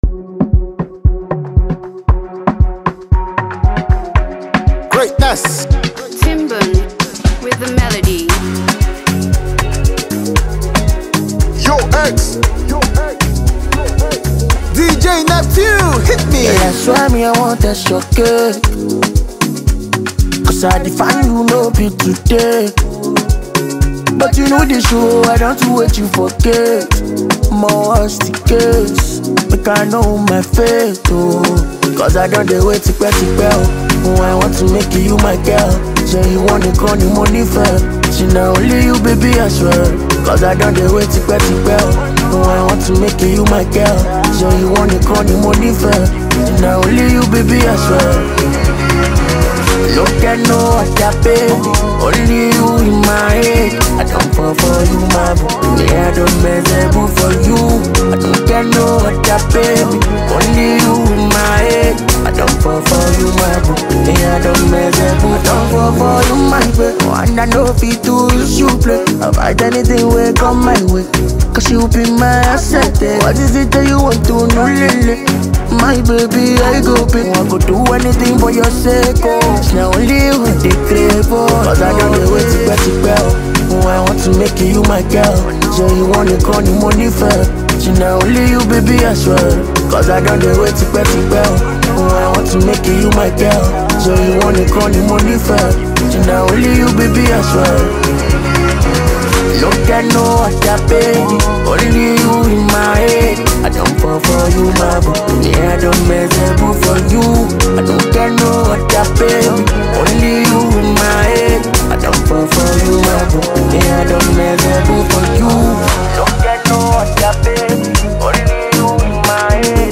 a sparkling and hypnotic magical music